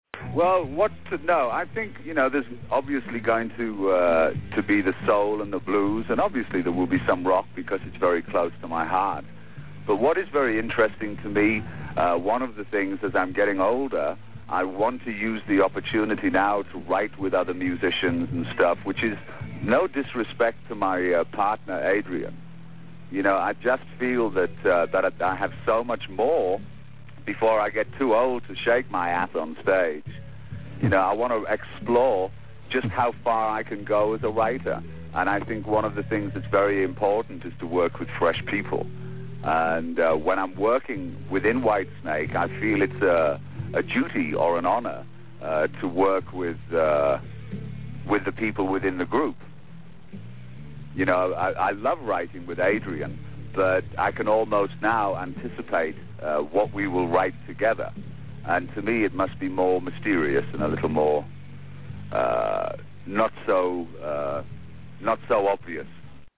Y una corta entrevista de Tarda Tardà de ese mismo julio de 1994
interview94.mp3